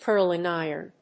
cúrling ìron
音節cúrling ìron